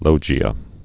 (lōgē-ə)